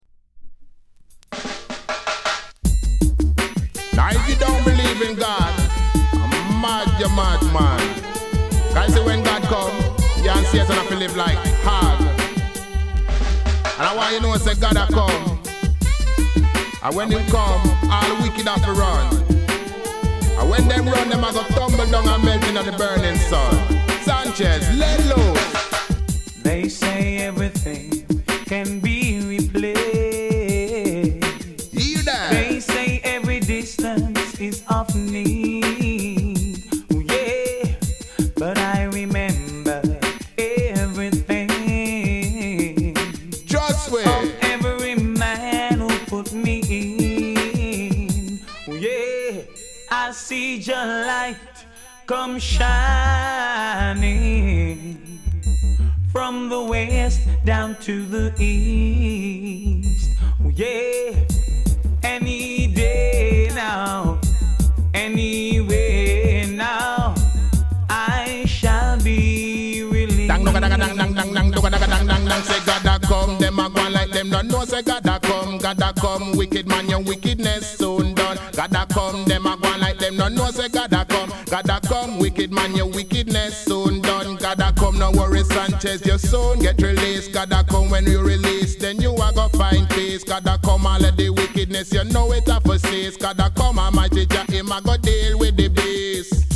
レゲエ